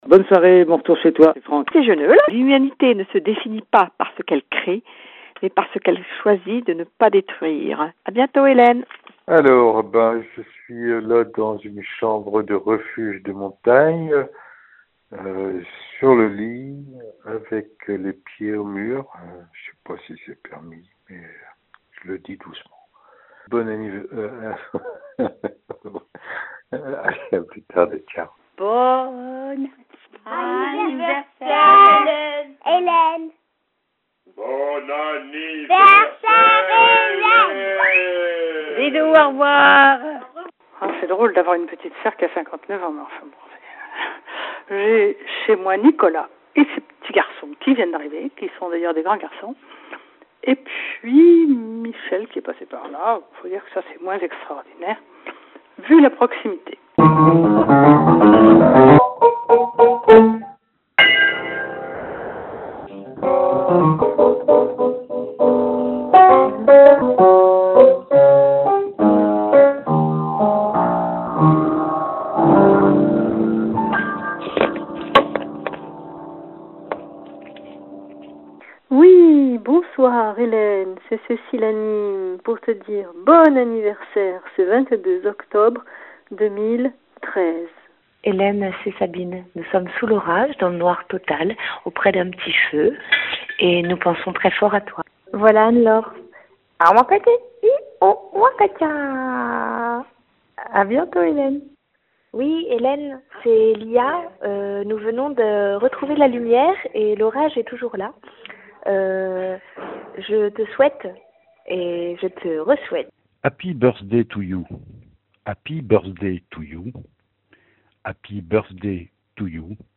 Bandes de Voix